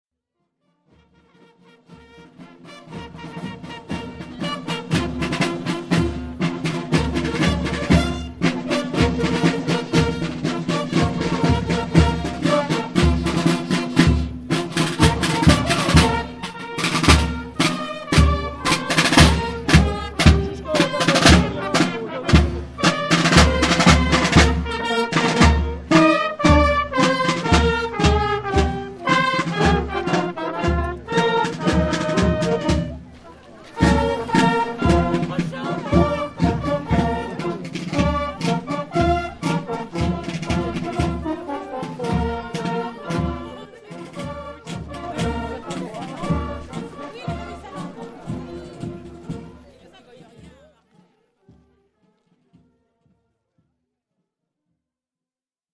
Le 14 juillet dernier, le petit village de Ciez, dans la Nièvre, avait sorti ses drapeaux et ses flonflons.
A l’heure convenue, la fanfare se met en route, précédant la retraite aux flambeaux où des bambins et leurs attentifs parents brandissent des lampions dans le vent.
La voilà qui arrive, cuivres et tambours finissant par couvrir le bruit des pétards.
Enregistrements numériques réalisés le 14 juillet 2009 sur matériel ZOOM H4.